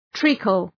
Προφορά
{‘tri:kəl}